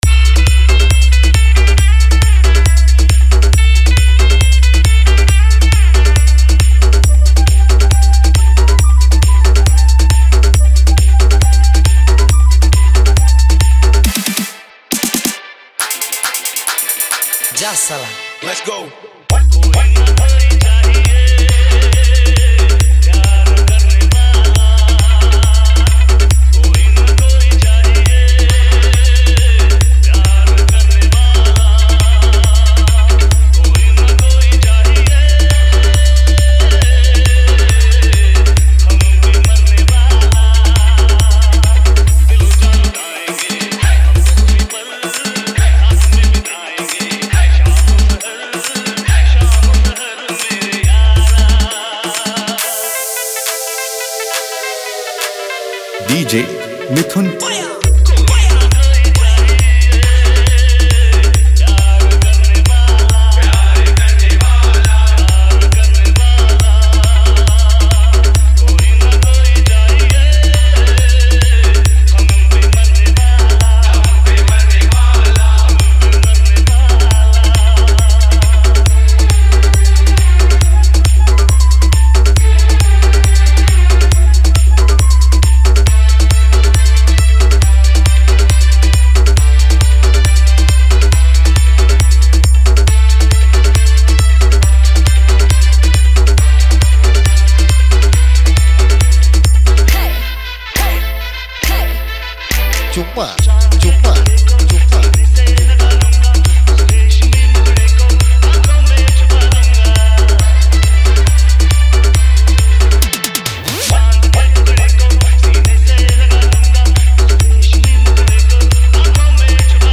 Hindi New Style Dance Dhamaka Mix